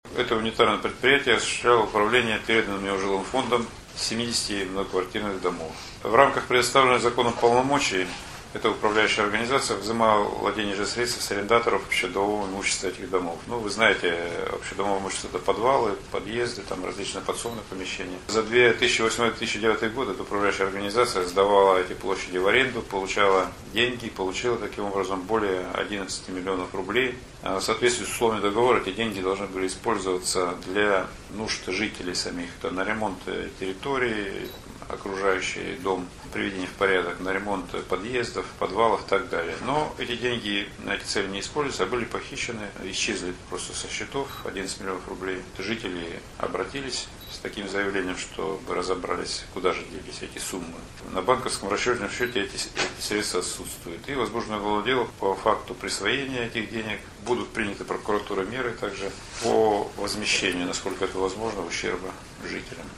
В Череповце управляющая компания обманула жильцов на 11 миллионов рублей. Об этом корреспонденту ИА «СеверИнформ — Новости Череповца» сообщил прокурор Вологодской области Сергей Хлопушин.
Сергей Хлопушин рассказывает о присвоении денег управляющей компанией